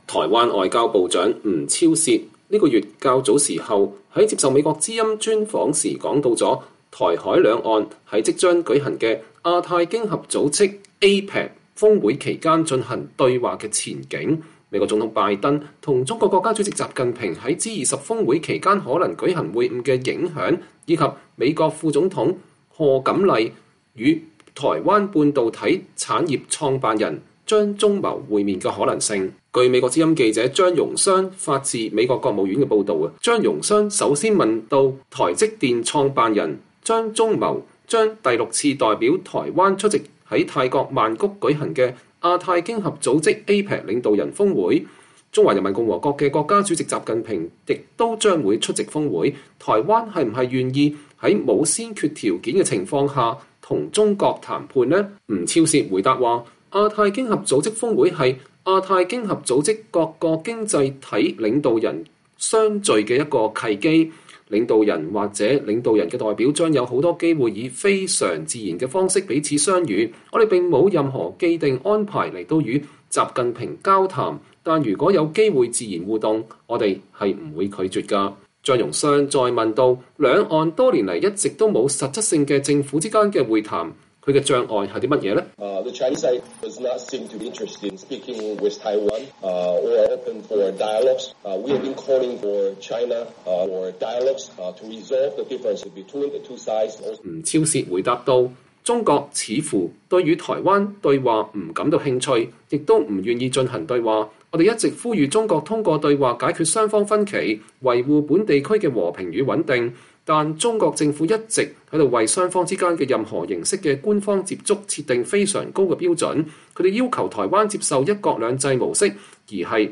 以下是Skype英文采訪摘錄的中文翻譯。